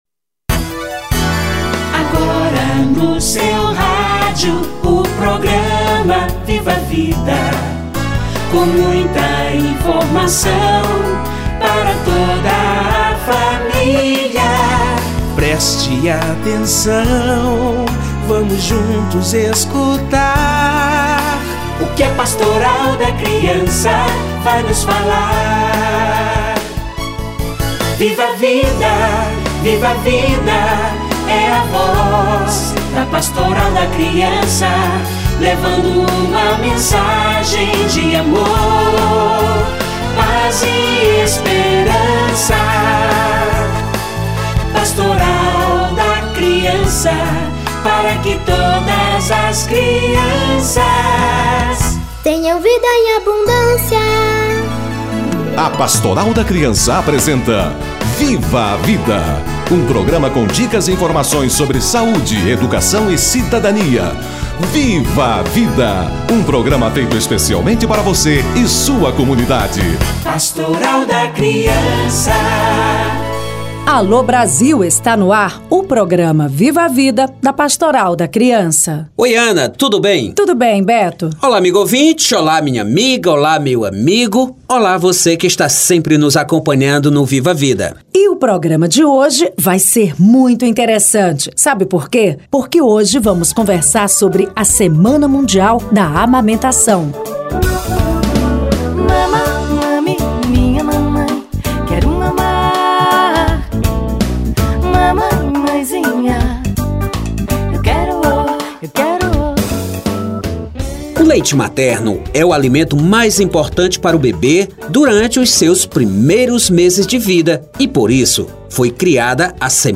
Semana mundial da amamentação - Entrevista